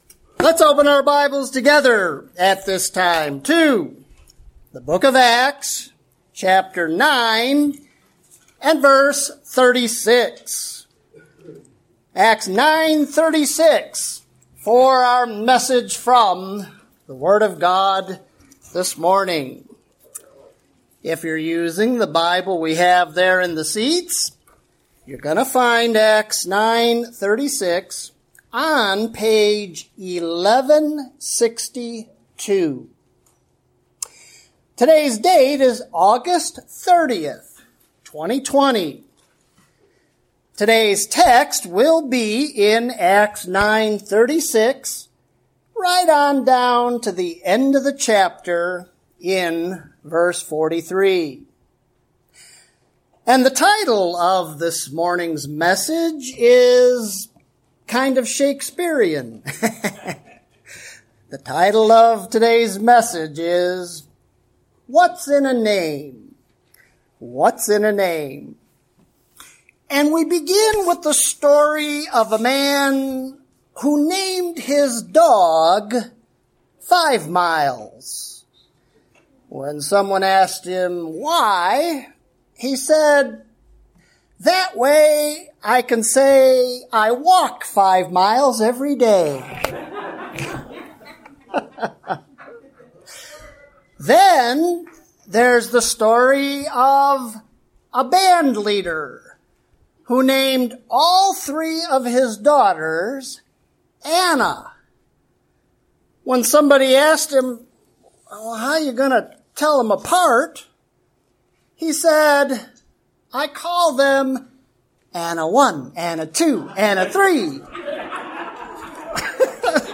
When you're done, explore more sermons from this series.